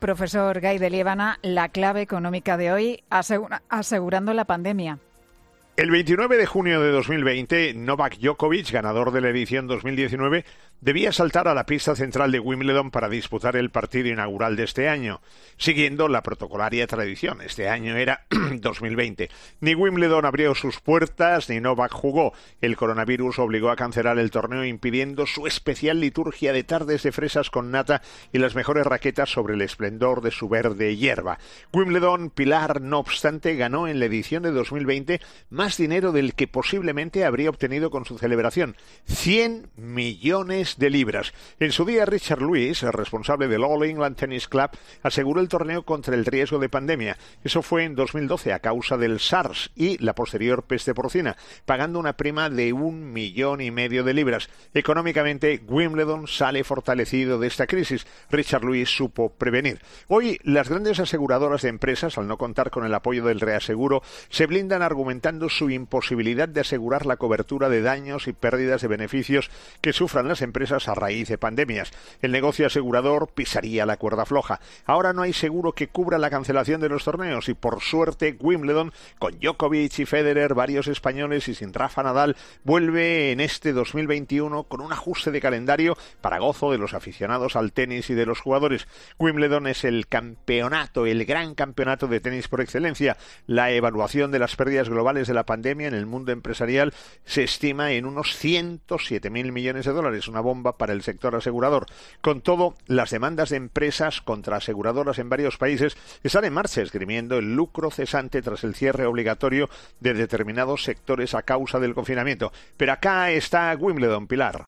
El profesor José María Gay de Liébana analiza en 'Herrera en COPE' las claves económicas del día.